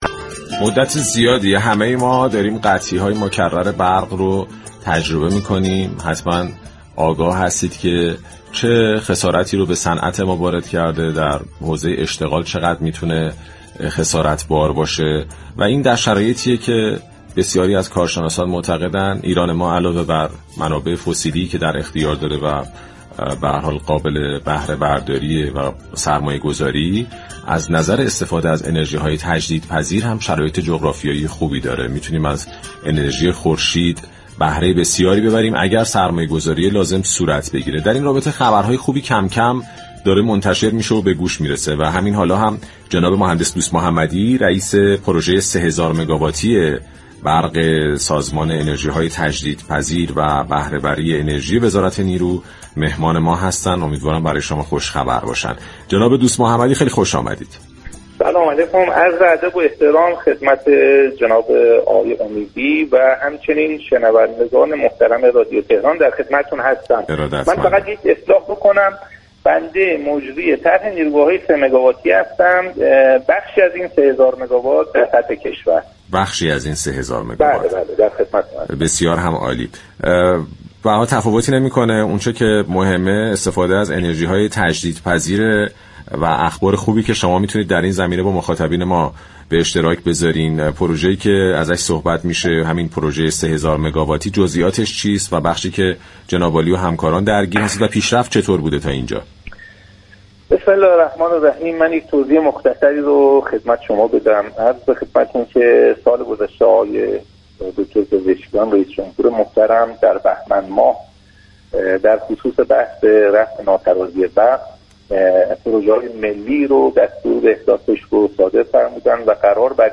در گفتگو با برنامه «بازار تهران» رادیو تهران